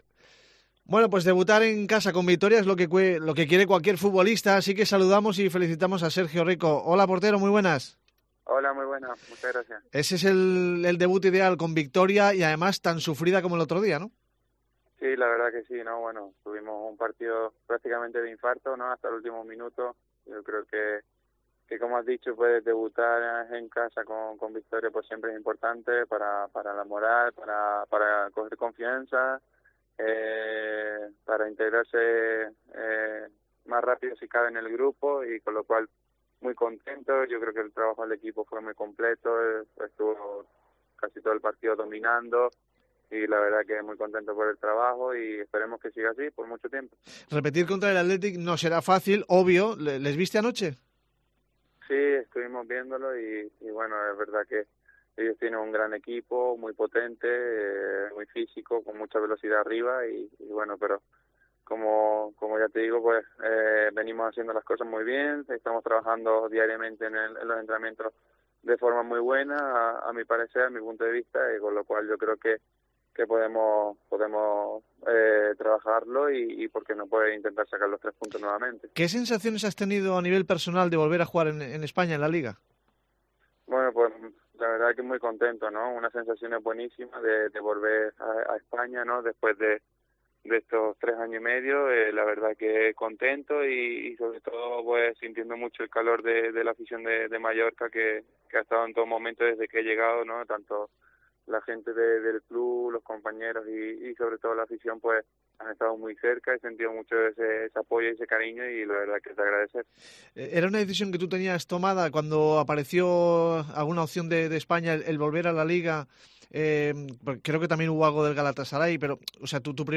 Entrevista al portero del RCD Mallorca tras su debut en casa